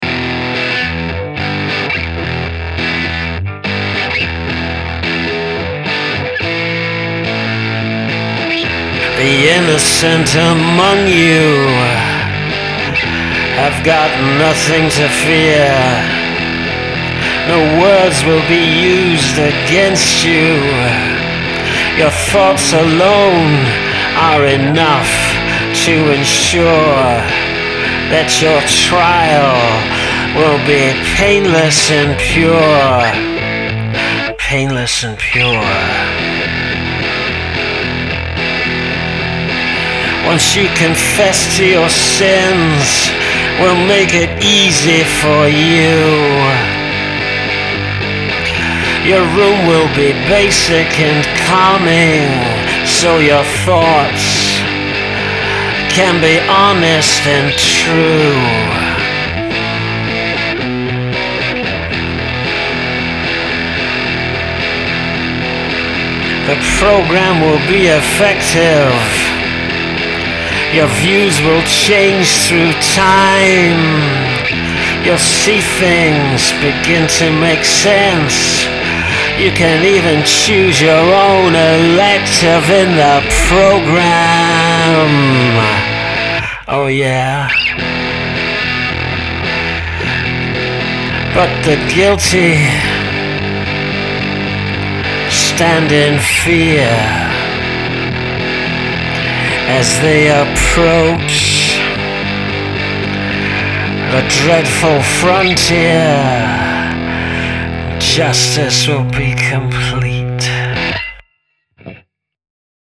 Oh it's one of those distopian, grungy ones apparently!
Kinda an Alice Cooper/ Clash combo.
British punk sound here! need a scream in there!